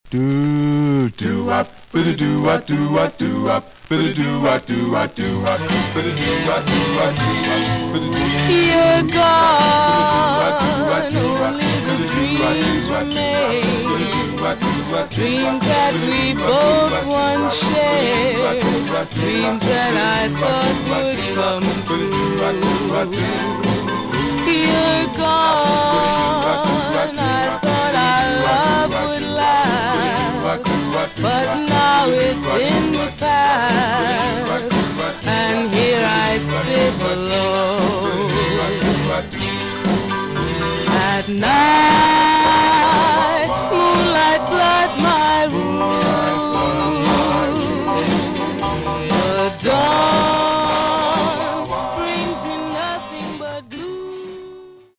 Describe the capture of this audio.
Stereo/mono Mono Some surface noise/wear